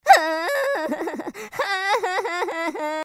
cry.mp3